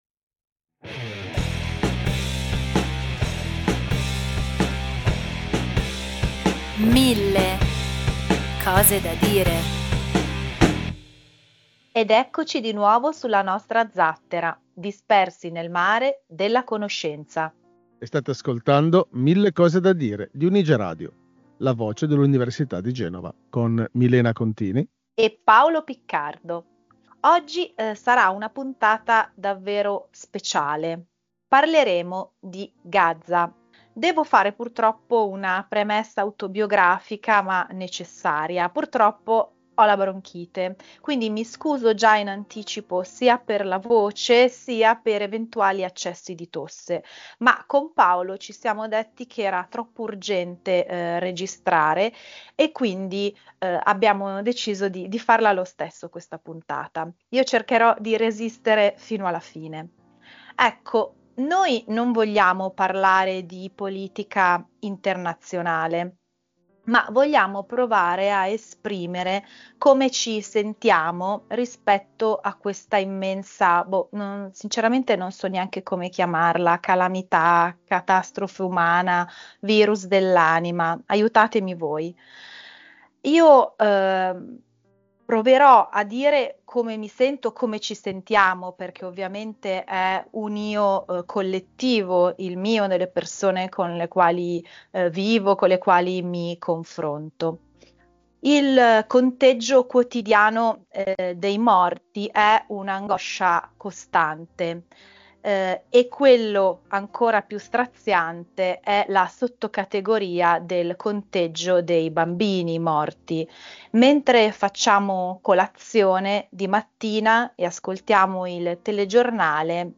Un dialogo emotivamente denso